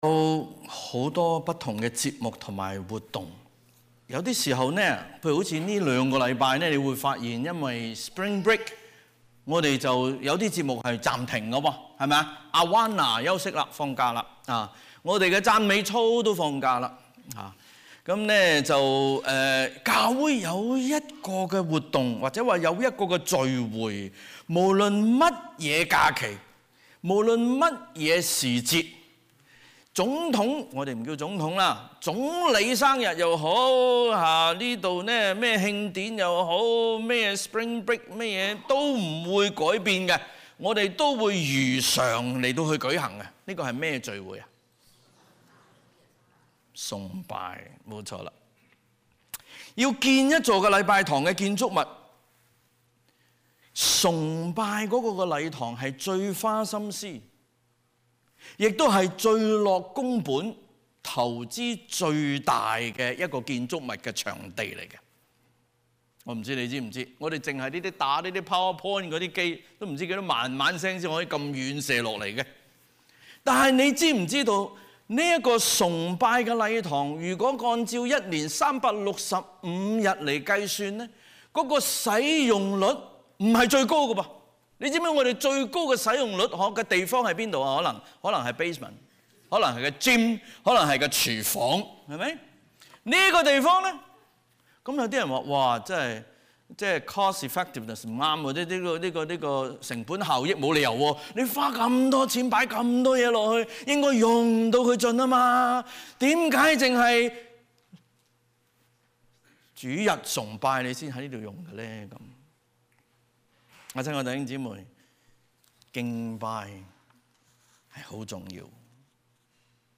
SERMONS | 講道 | Westwood Alliance Church